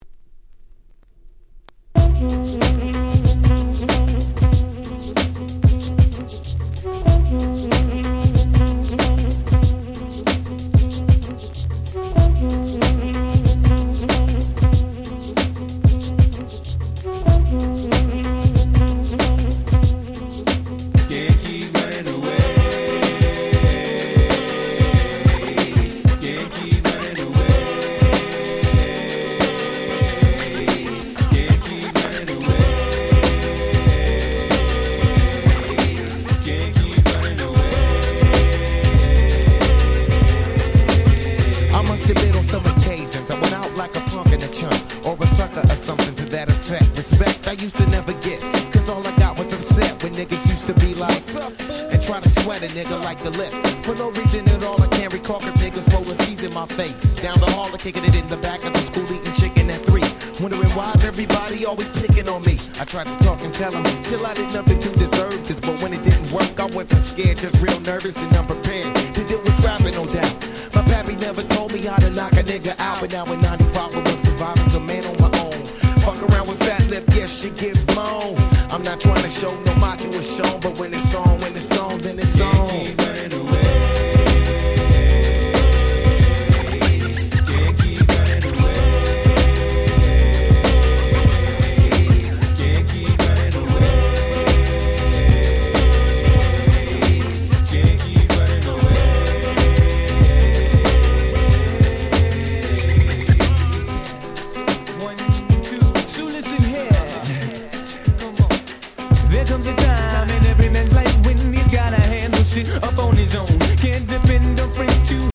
本盤は90'sの人気Hip Hop Classicsばかりを全6曲収録！！